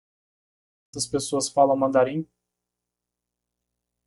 Pronounced as (IPA) /mɐ̃.daˈɾĩ/